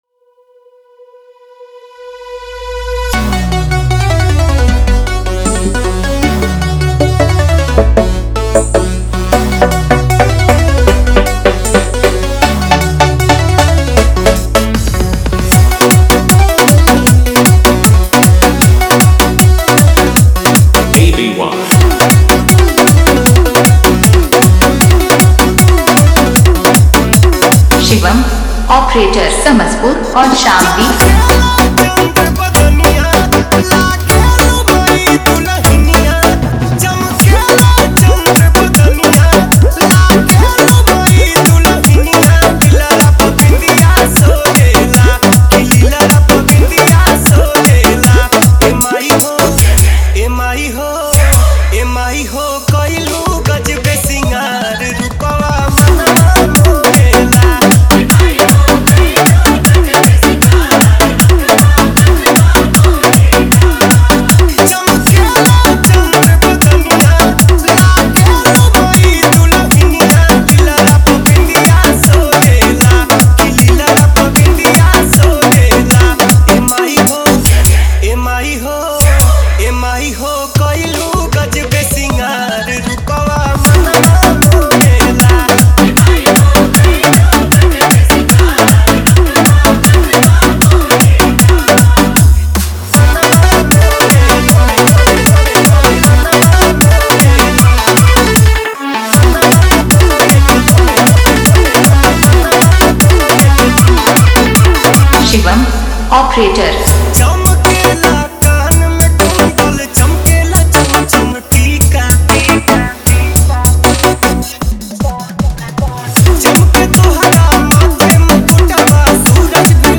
Navratri New Latest Dj Remix Songs